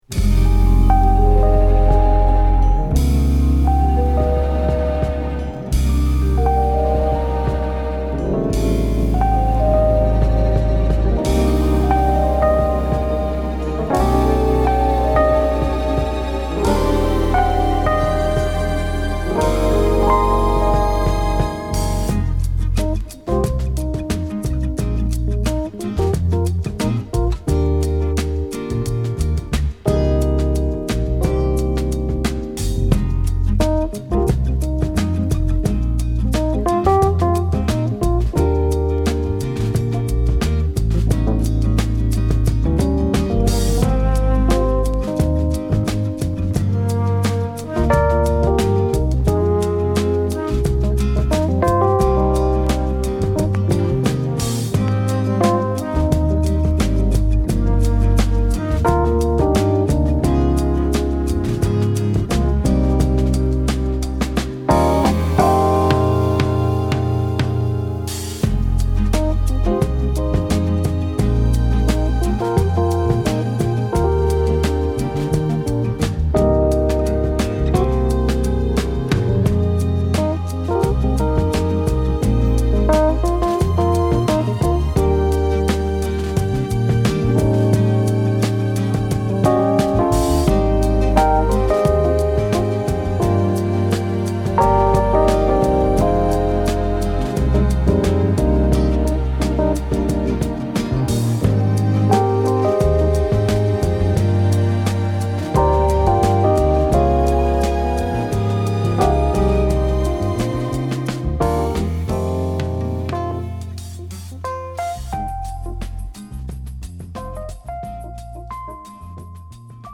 メロウなエレピが心地よいナイスヴァージョンです！